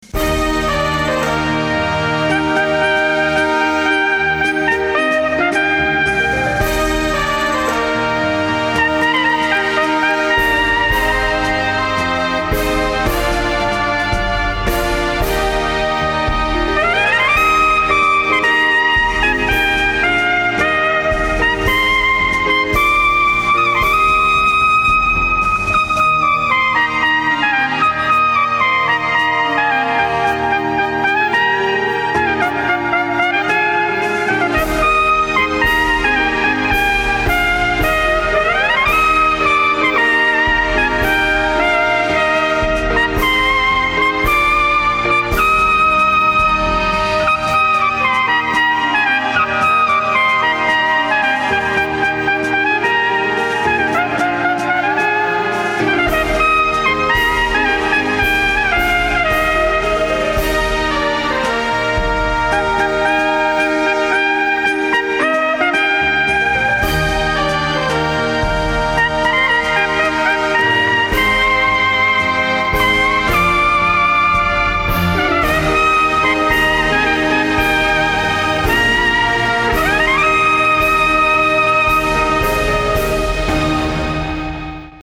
クリスマスの曲ばかり全24曲をピッコロトランペットで吹いて録音したものです。
(昔の)僕の音　（ピッコロトランペット）